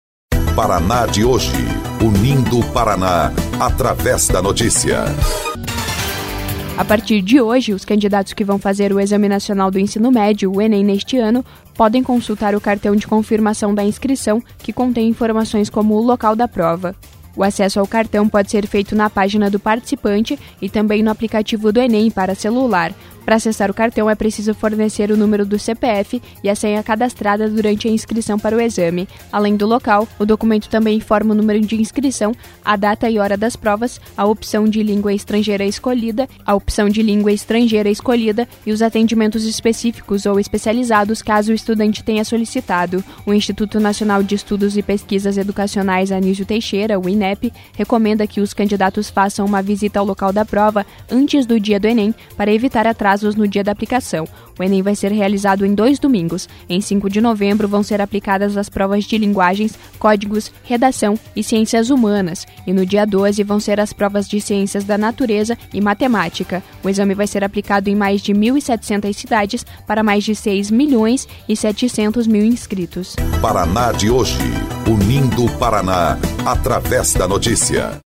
20.10 – BOLETIM – Candidatos do Enem podem consultar o cartão de confirmação a partir de hoje